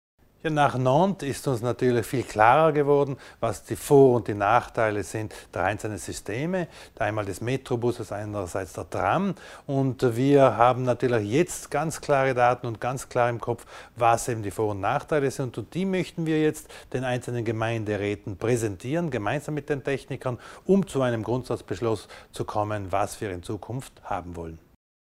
Landesrat Thomas Widmann erklärt warum das Projekt Überetsch Vorrang hat